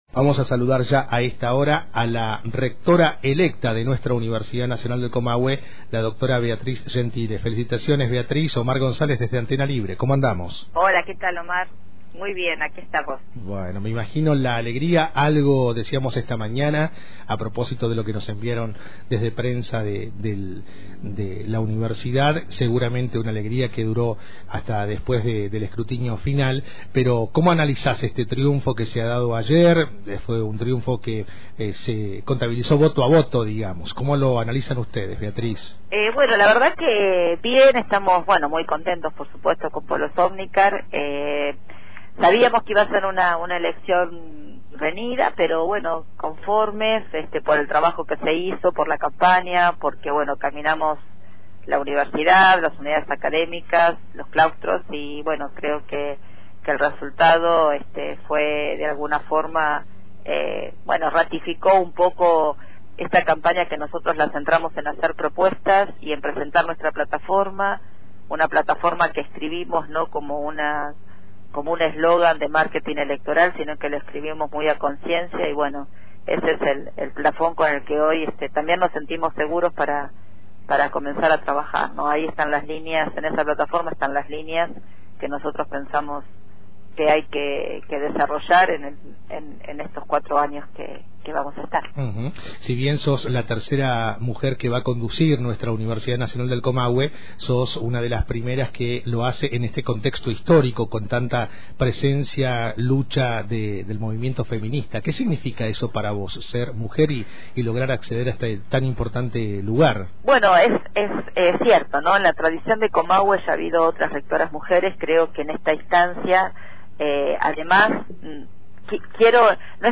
Para escuchar la nota completa con la rectora de la Unco, Beatriz Gentile, clickeá acá: